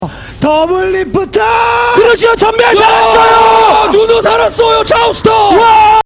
Doublelift shoutcast